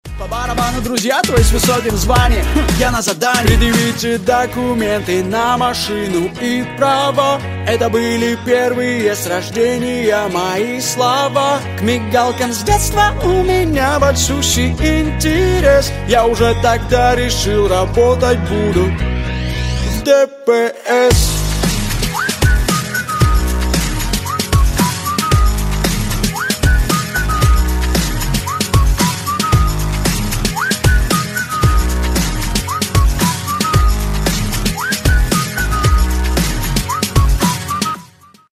Кавер И Пародийные Рингтоны